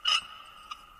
Divergent/lamp_squeaks_2.ogg at 328d67128d658f2cc767bedffedb5bc97b7a0a30
lamp_squeaks_2.ogg